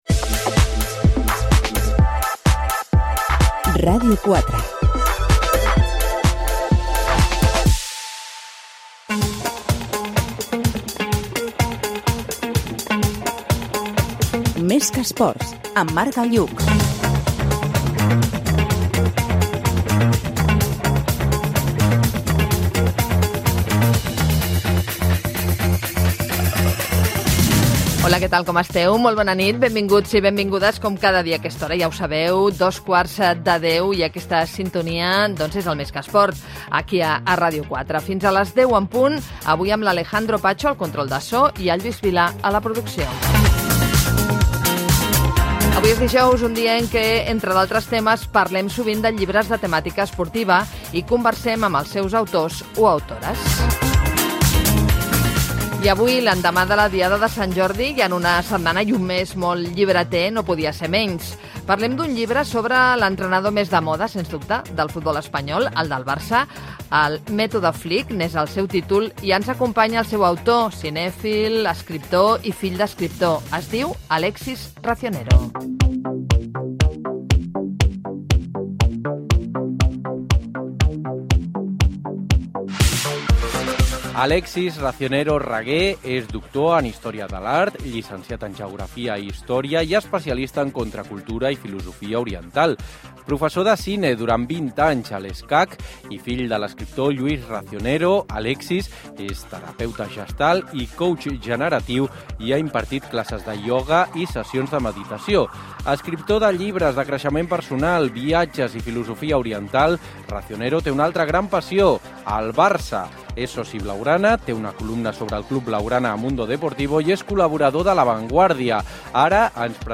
Indicatiu de la ràdio, careta del programa, benvinguda, perfil biogràfic i entrevista
Gènere radiofònic Esportiu